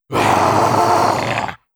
RiftMayhem / Assets / 1-Packs / Audio / Monster Roars / 05.
05. Chilling Roar.wav